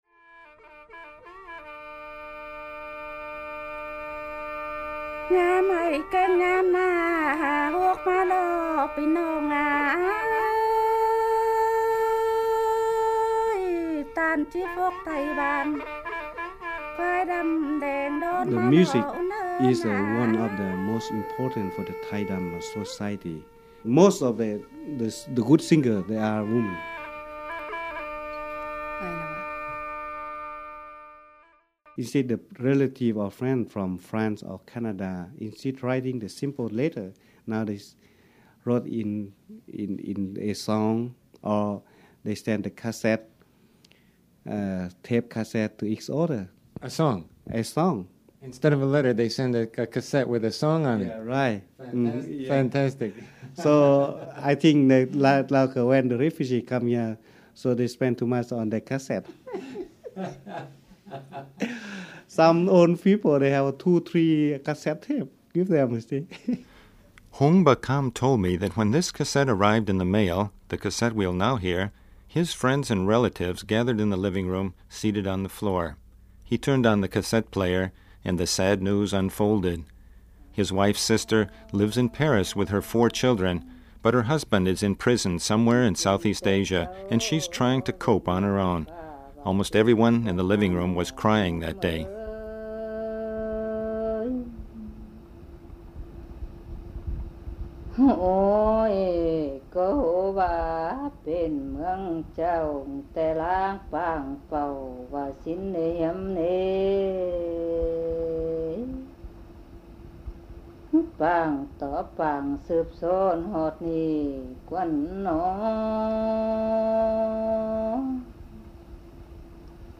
TAI DAM   "Black Tai" music from Southeast Asia, now in Iowa